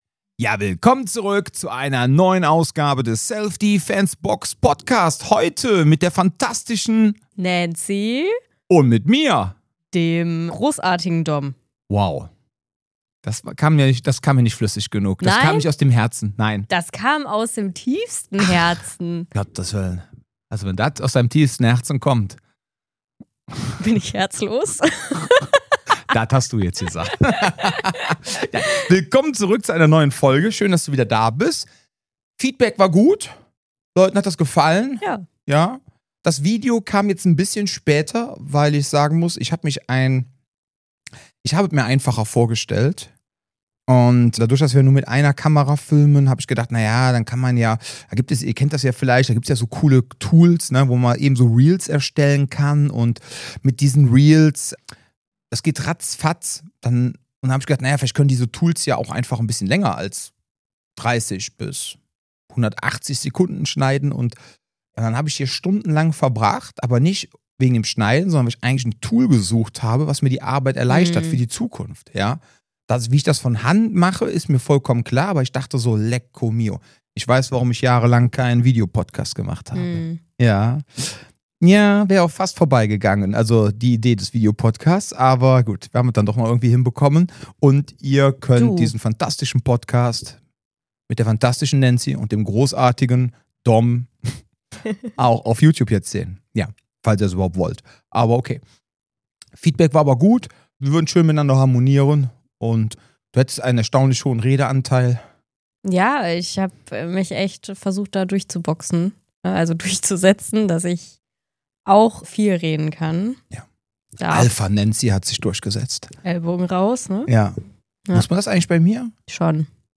Beschreibung vor 1 Monat In dieser neuen Ausgabe des Selfdefensebox Podcast starten wir mit unserem frischen Konzept: Rubriken, mehr Struktur und trotzdem das gleiche lockere Hin-und-her.